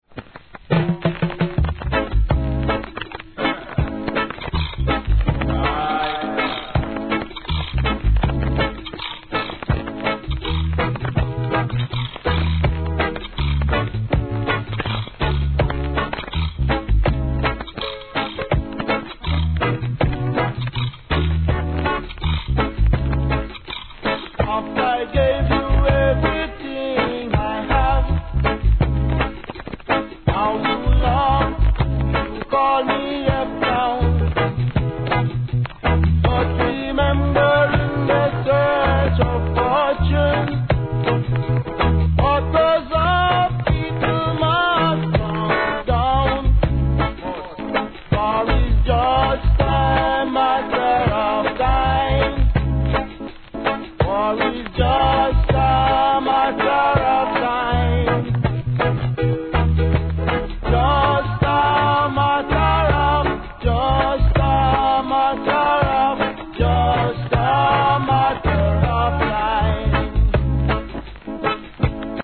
REGGAE
1973年、深〜い味のあるNICEヴォーカル♪